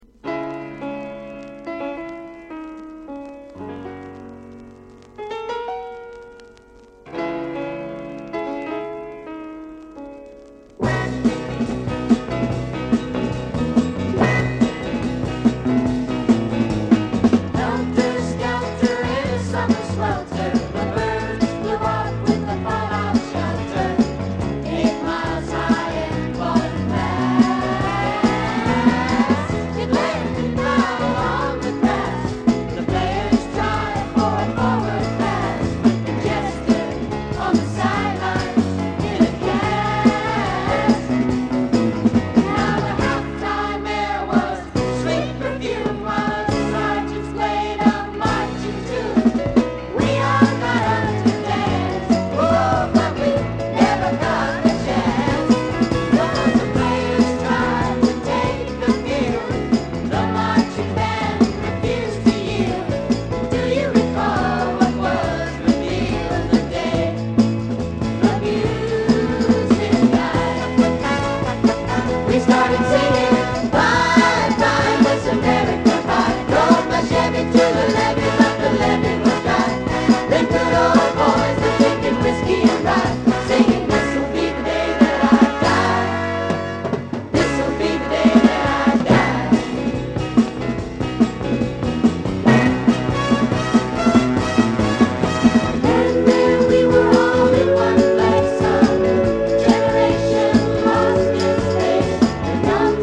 KIDS SOFT ROCKコーラスの大傑作！大人気キッズソウル